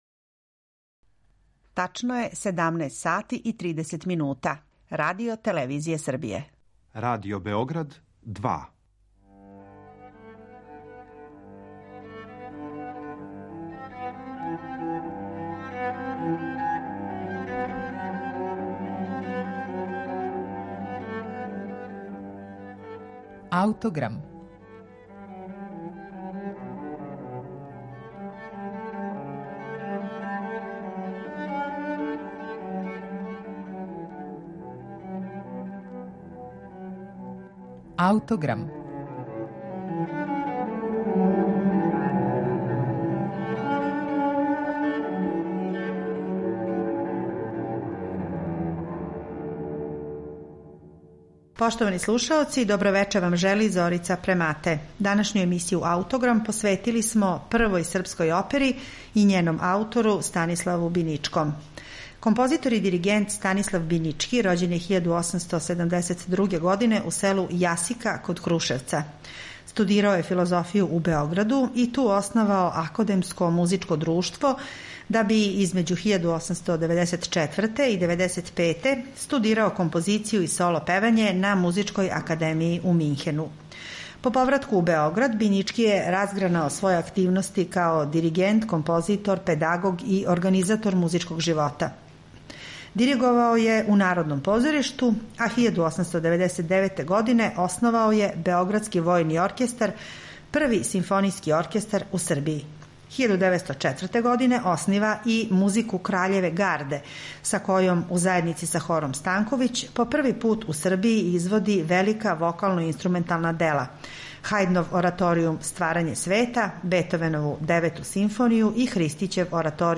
припремила је интегралну верзију ове једночинке, коју ћете слушати са нашег архивског снимка начињеног 1968. године.
сопран
мецосопран
тенор
бас. У извођењу учествују и Хор и Симфонијски оркестар РТС-а